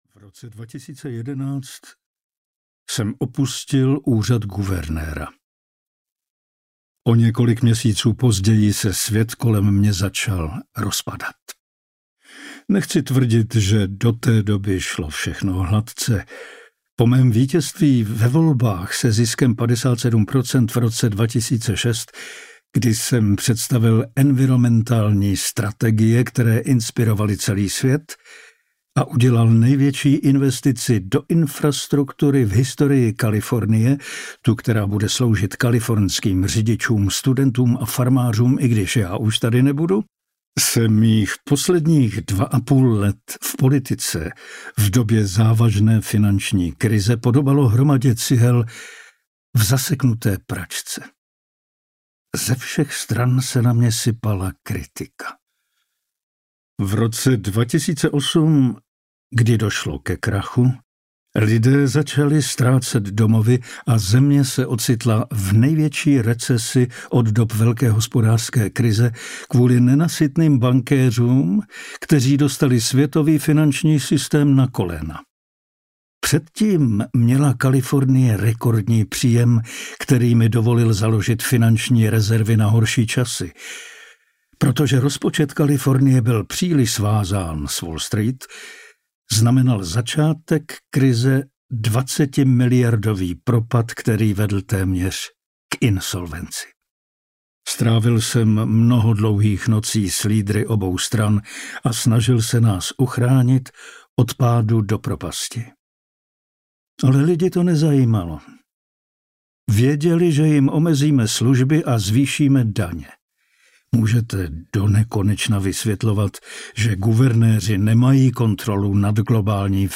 Buď užitečný audiokniha
Ukázka z knihy
• InterpretPavel Soukup